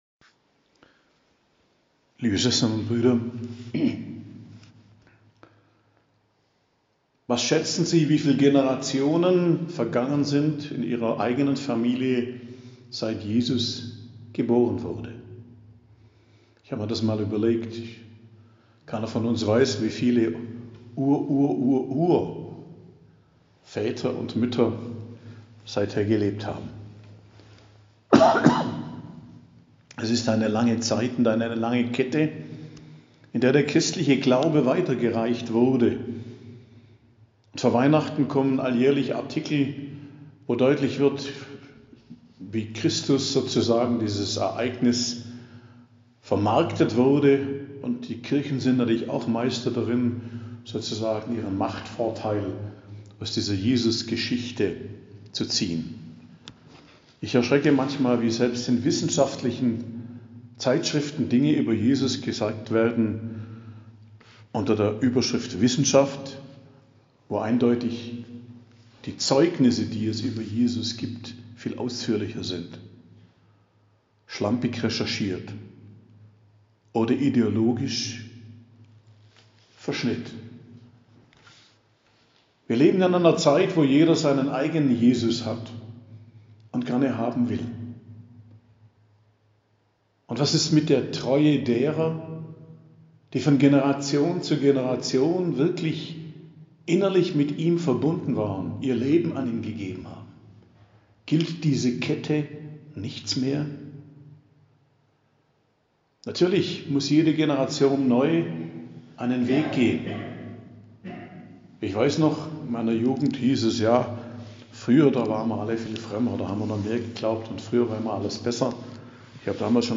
Predigt am Freitag der 4. Woche im Advent, 23.12.2022